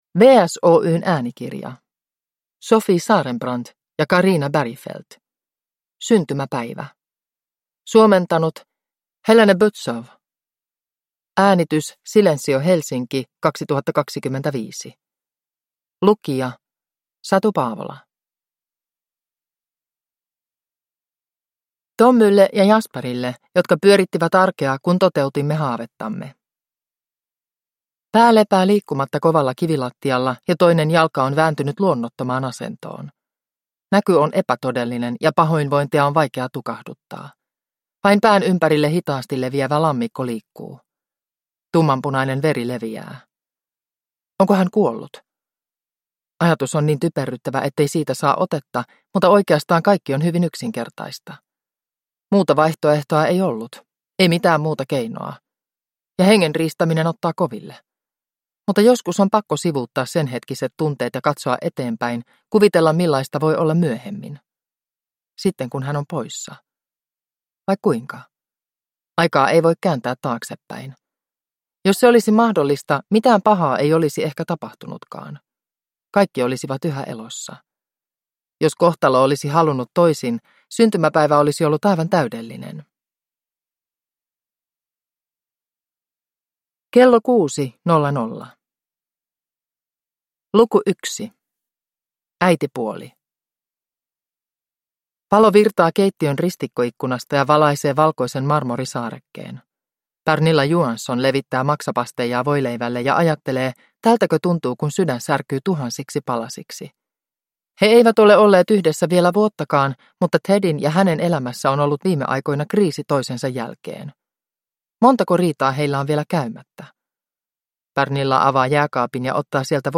Syntymäpäivä (ljudbok) av Sofie Sarenbrant